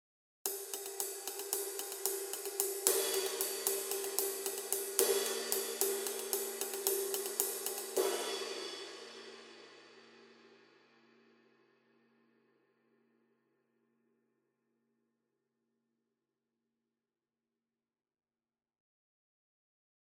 Это обеспечивает невероятно широкий динамический диапазон, землистый теплый звук, четкую атаку и отличную читаемость в миксе.
20" Flat
Masterwork 20 Custom Pointer Flat Ride sample
CustomPointer-Ride-20-Flat.mp3